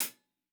TC Live HiHat 13.wav